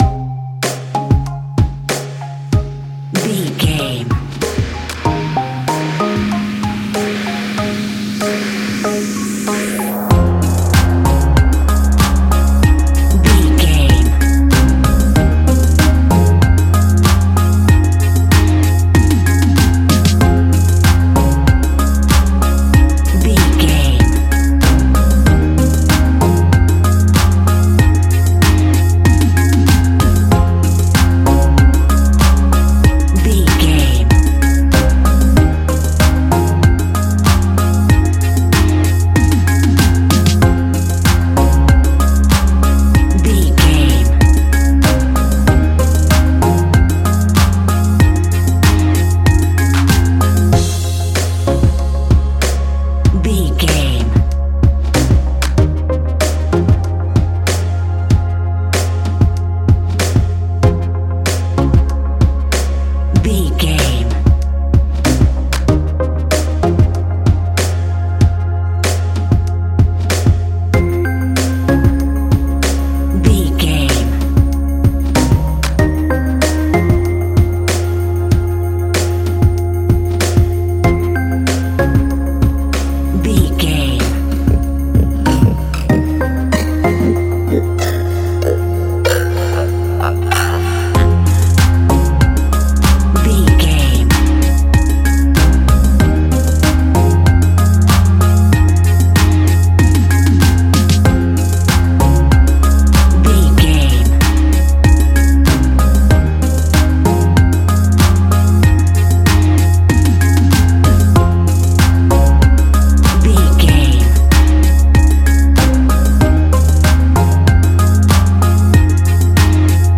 Ionian/Major
C♭
house
electro dance
synths
techno
trance